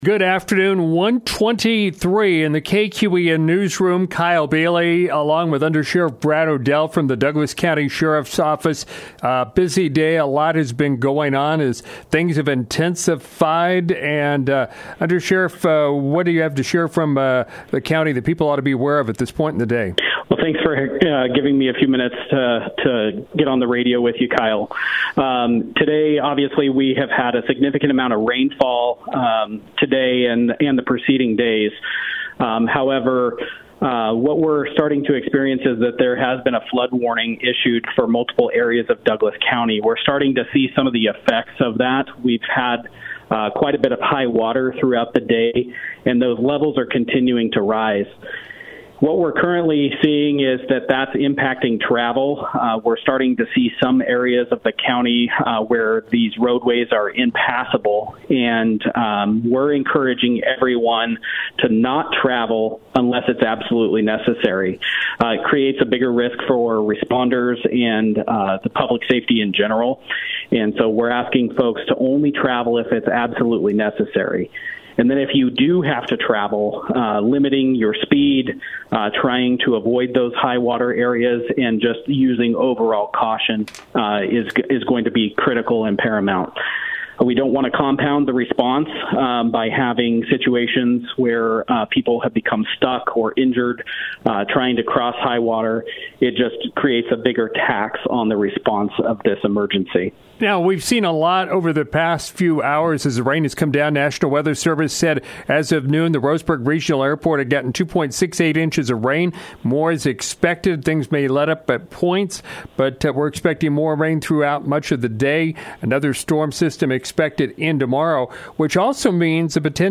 Sunday afternoon at 1:20 p.m., Undersheriff Brad O'Dell of the Douglas County Sheriff's Office joined us live on KQEN to talk about the flood warnings issued for much of the county, road slides, and other emergency information to deal with the current conditions.